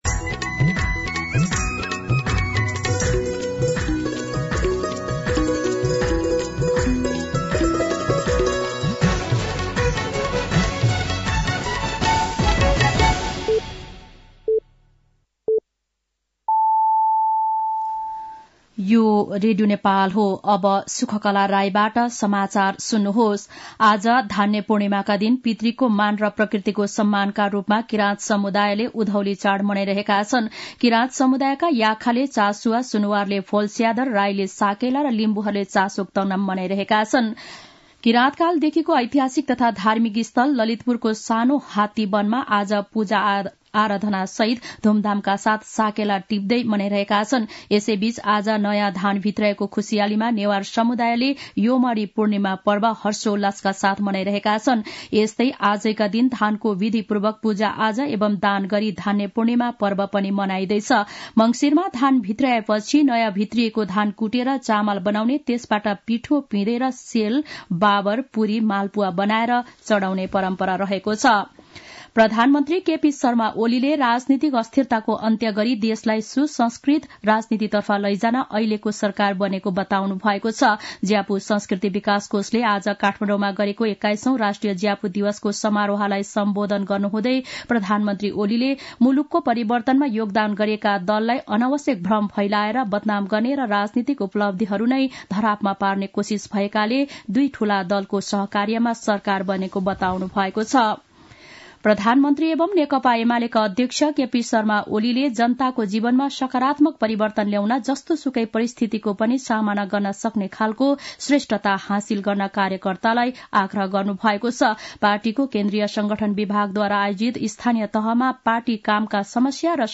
साँझ ५ बजेको नेपाली समाचार : १ पुष , २०८१
5-PM-Nepali-News-8-30.mp3